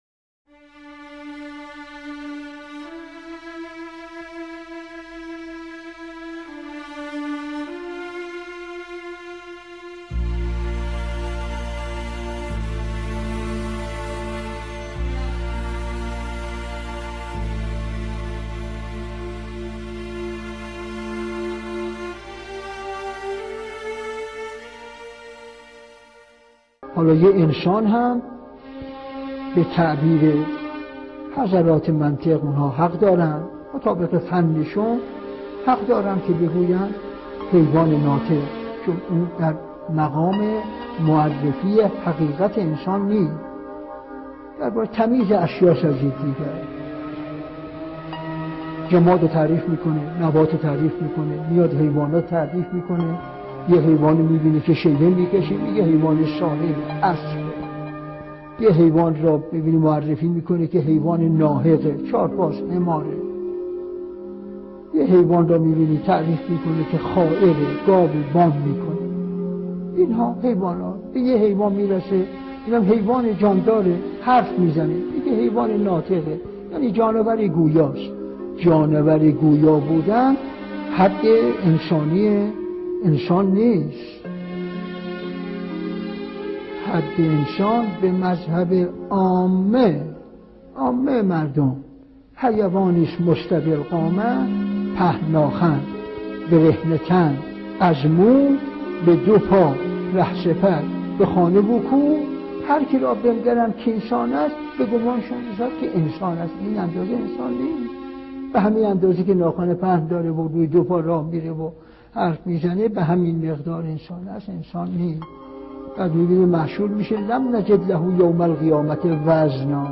درس اخلاق | عزیز من انسان حیوان ناطق نیست
به گزارش خبرگزاری حوزه، مرحوم علامه حسن زاده آملی در یکی از دروس اخلاق خود به موضوع «حقیقیت وجودی انسان ها» پرداختند که تقدیم شما فرهیختگان می شود.